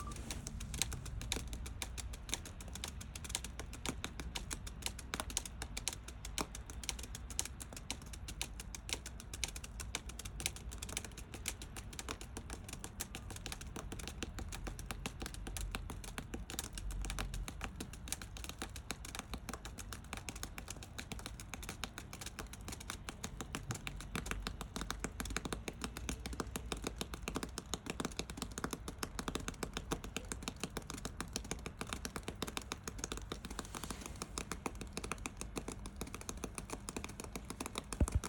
(Please use headphones or good speakers, phone speakers sound awful and you can’t hear the soundtrack properly)